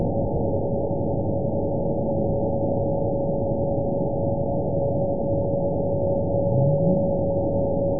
event 914071 date 04/26/22 time 23:18:50 GMT (3 years ago) score 9.50 location TSS-AB01 detected by nrw target species NRW annotations +NRW Spectrogram: Frequency (kHz) vs. Time (s) audio not available .wav